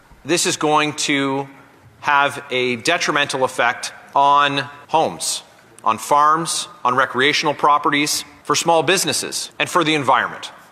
The Leeds-Grenville-Thousand Islands-Rideau Lakes MP spoke in the House of Commons for nearly 15 minutes, highlighting his objections.